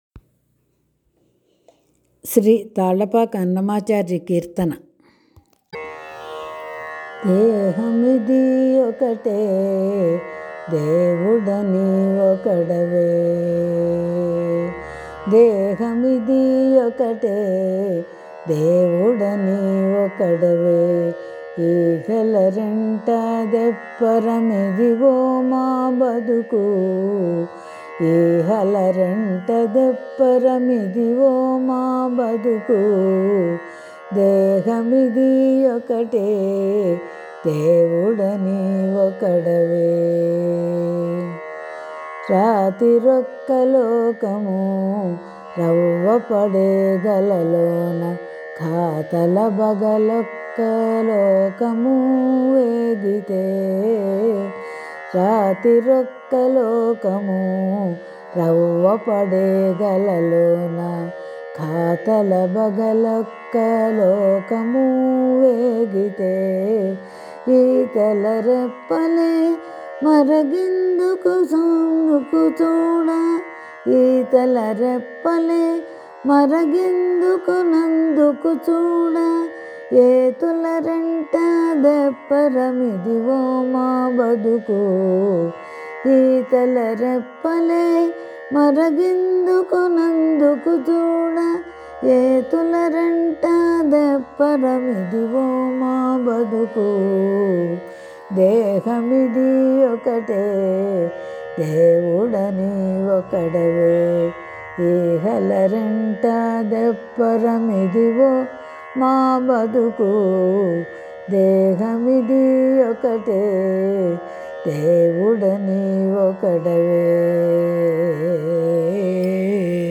భక్తిగీతాలు
కీర్తన